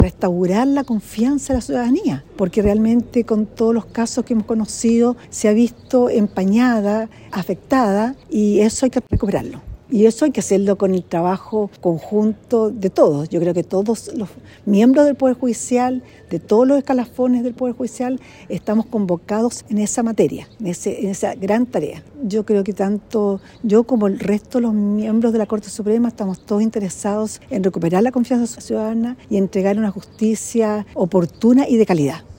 Sus declaraciones las entregó en el contexto de la inauguración del nuevo Juzgado de Garantía y Tribunal Oral en lo Penal de Osorno, región de Los Lagos; edificio con una superficie de 5.231 metros cuadrados que presta servicios a una población superior a 220 mil personas de la provincia de Osorno.